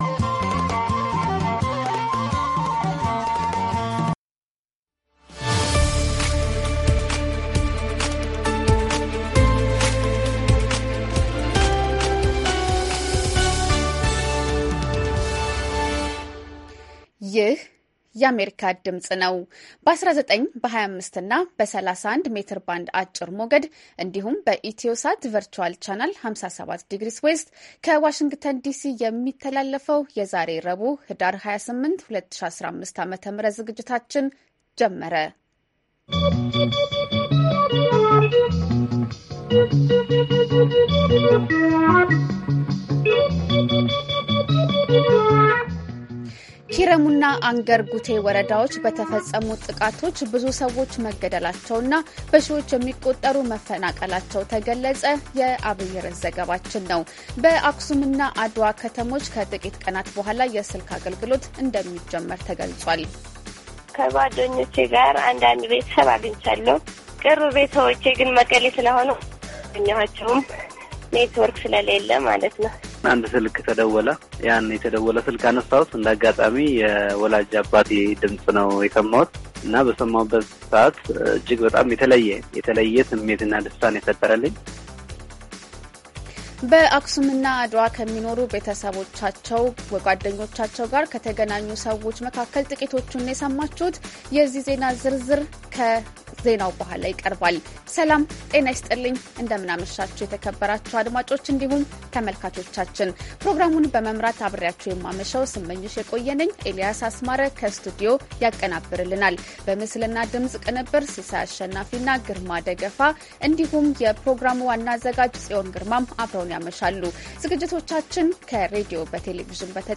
ረቡዕ፡-ከምሽቱ ሦስት ሰዓት የአማርኛ ዜና
ቪኦኤ በየዕለቱ ከምሽቱ 3 ሰዓት በኢትዮጵያ አቆጣጠር ጀምሮ በአማርኛ፣ በአጭር ሞገድ 22፣ 25 እና 31 ሜትር ባንድ የ60 ደቂቃ ሥርጭቱ ዜና፣ አበይት ዜናዎች ትንታኔና ሌሎችም ወቅታዊ መረጃዎችን የያዙ ፕሮግራሞች ያስተላልፋል። ረቡዕ፡- ዴሞክራሲ በተግባር፣ ሴቶችና ቤተሰብ፣ አሜሪካና ሕዝቧ፣ ኢትዮጵያዊያን ባሜሪካ